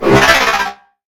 combat / enemy / droid / att3.ogg